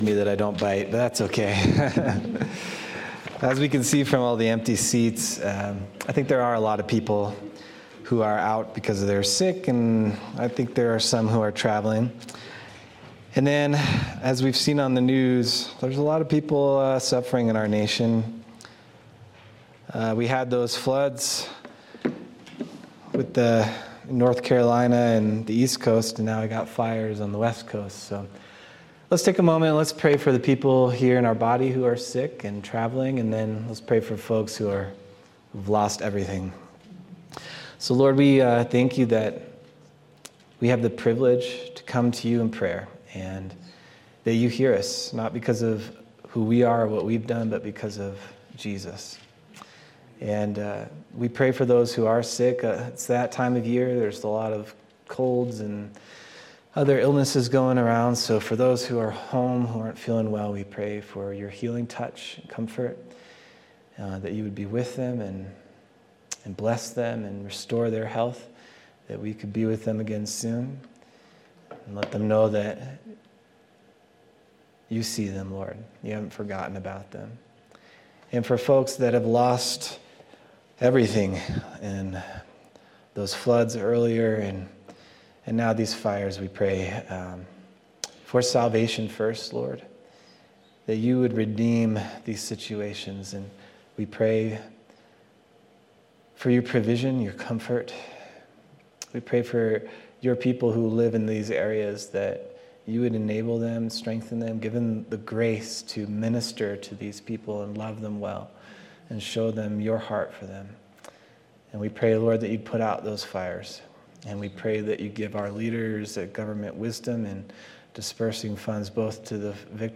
January 12th, 2025 Sermon